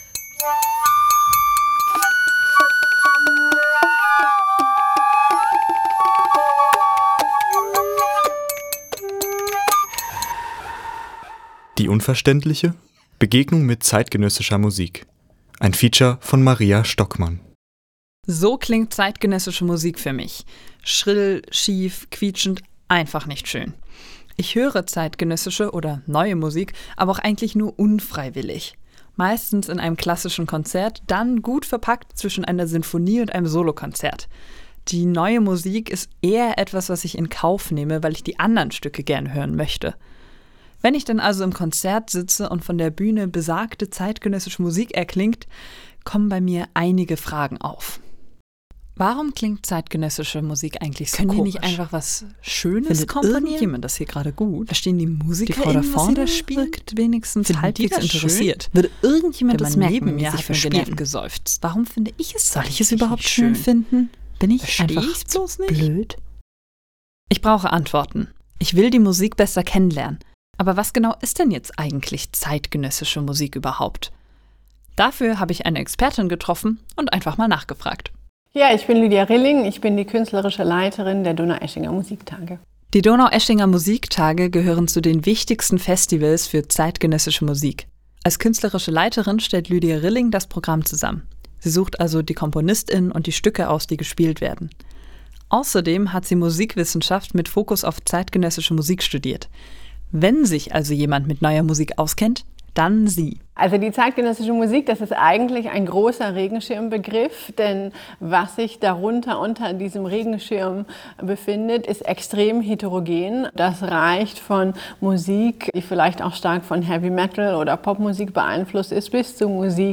Welche Musikstücke mir dabei geholfen haben und welche Rolle die aktuelle gesellschaftliche Situation dabei spielt, könnt Ihr in meinem Feature hören!